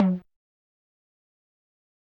Metro Perc 5.wav